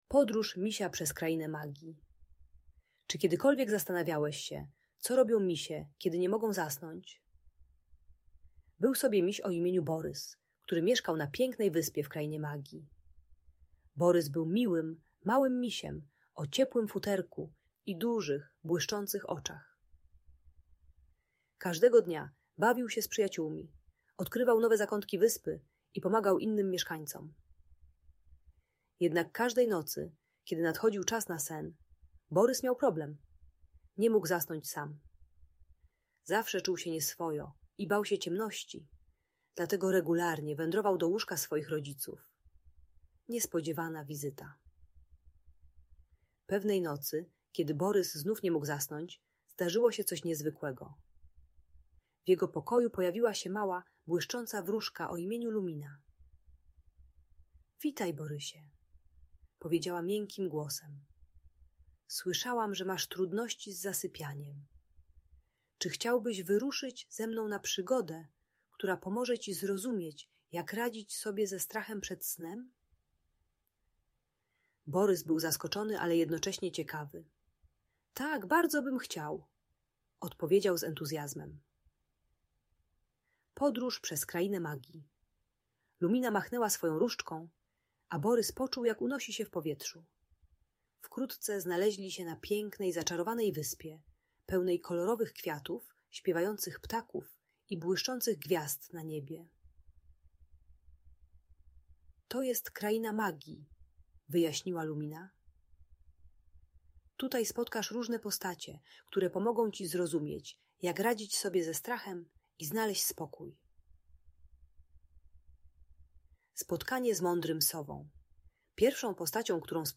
Podróż Misia Borysa przez Krainę Magii - Bajkowa Opowieść - Audiobajka